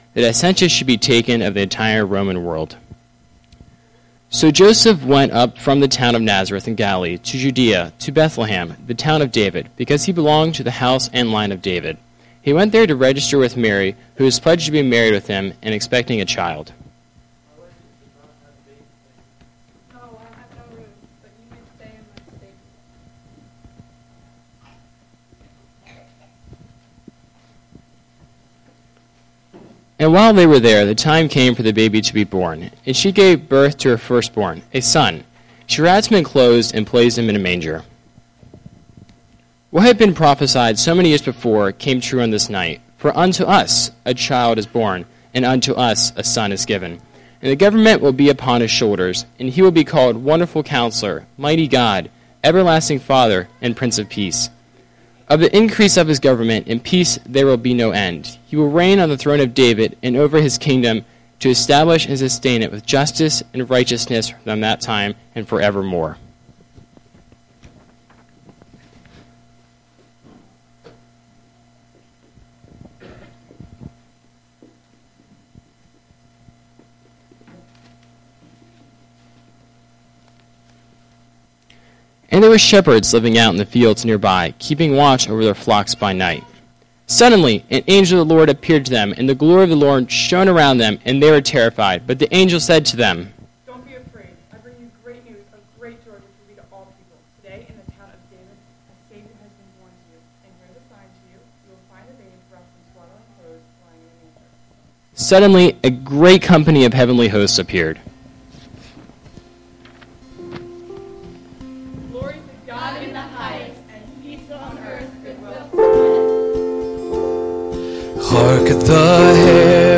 A Special Christmas Service